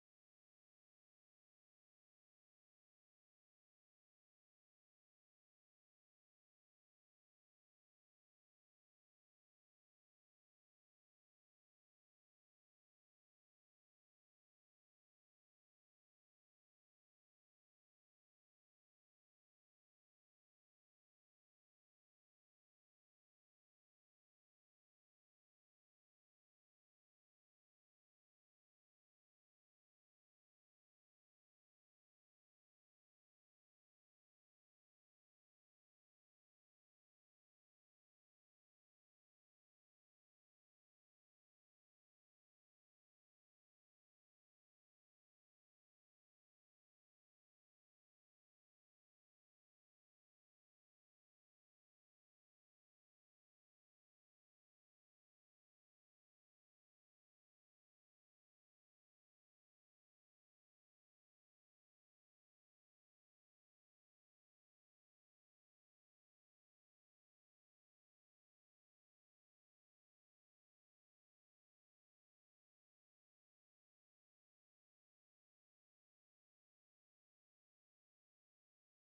Index of /SkyCloud/Audio_Post_Production/Education/The Ways/2026-01-29 Ice Fishing Camp - Mole Lake/Day 3 - 1-31